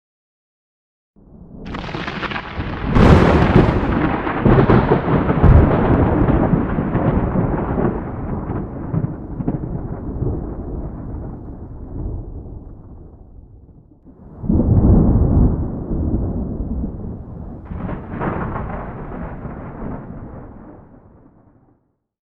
thunder-0-hec.ogg